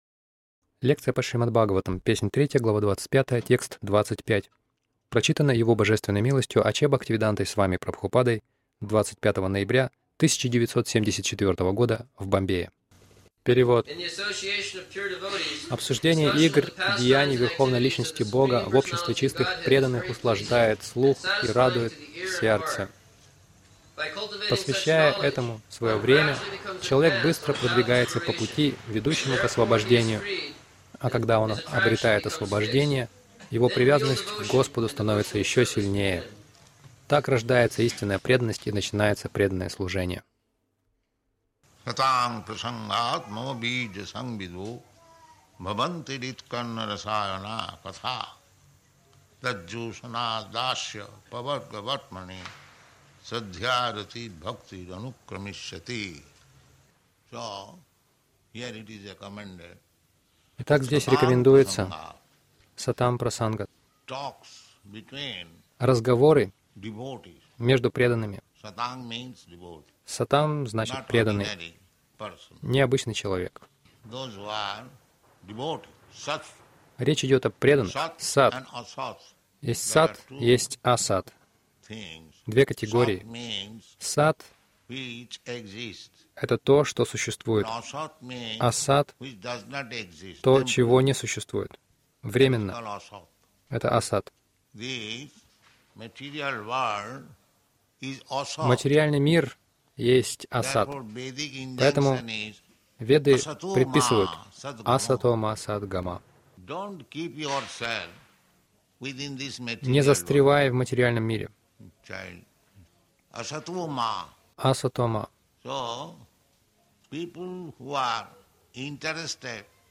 Милость Прабхупады Аудиолекции и книги 25.11.1974 Шримад Бхагаватам | Бомбей ШБ 03.25.25 — Примите Сознание Кришны Загрузка...